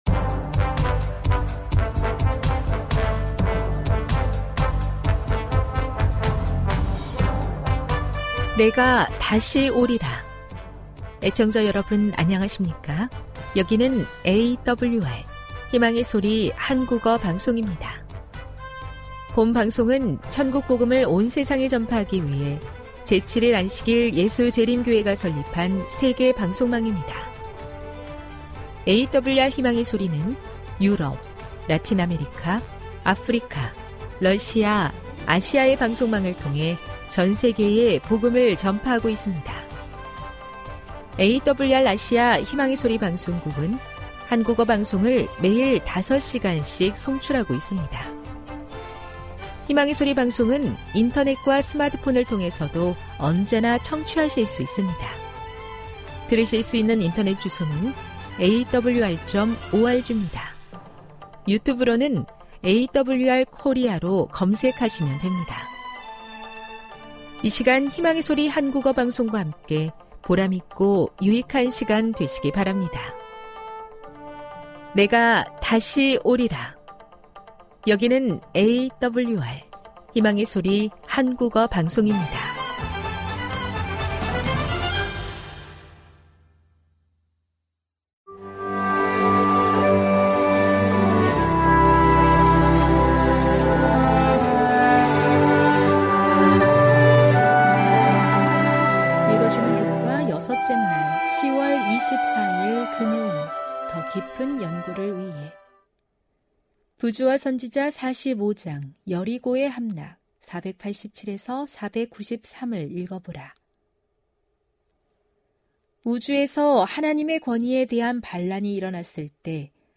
1 설교, 말씀묵상 59:00